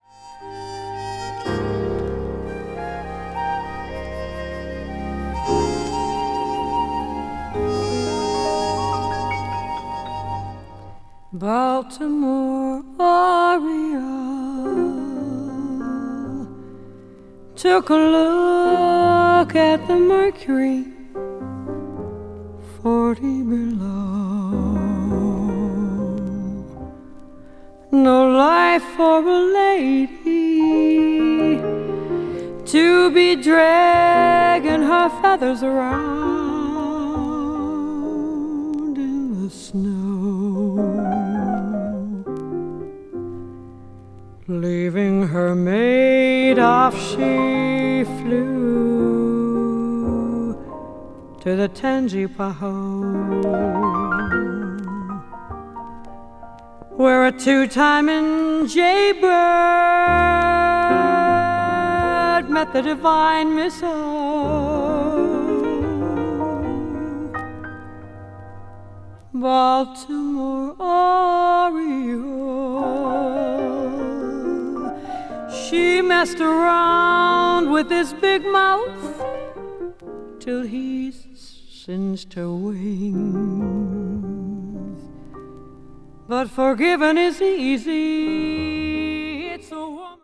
media : VG+/VG+(わずかなチリノイズ/一部軽いチリノイズが入る箇所あり,再生音に影響ない薄い擦れあり)
jazz ballad   jazz standard   jazz vocal   mellow jazz